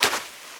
STEPS Sand, Walk 09.wav